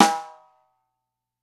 5 snare.WAV